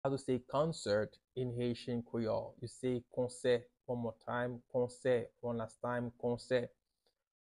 “Concert” means "konsè" in Haitian Creole – “Konsè” pronunciation by a native Haitian teacher
“Konsè” Pronunciation in Haitian Creole by a native Haitian can be heard in the audio here or in the video below:
How-to-say-Concert-in-Haitian-Creole-–-Konse-pronunciation-by-a-native-Haitian-teacher.mp3